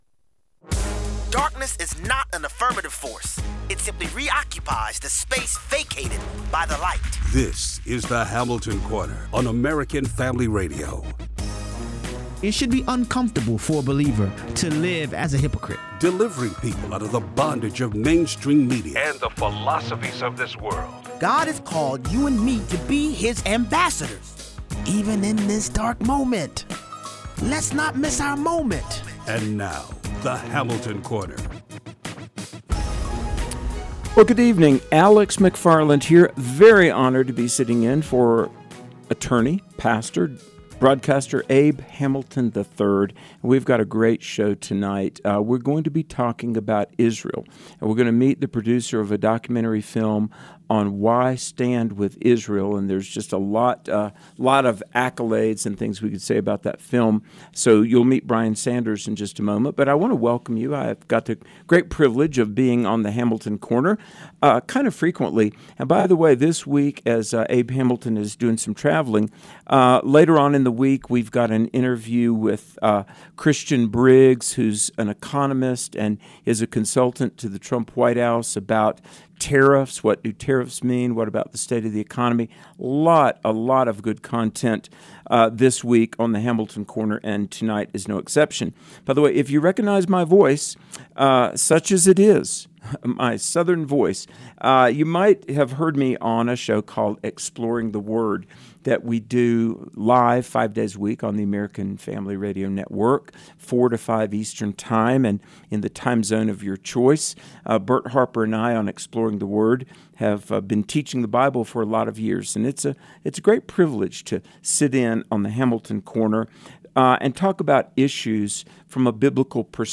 Guest Host